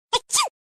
Nada dering Pikachu Bersin
Kategori: Nada dering
nada-dering-pikachu-bersin-id-www_tiengdong_com.mp3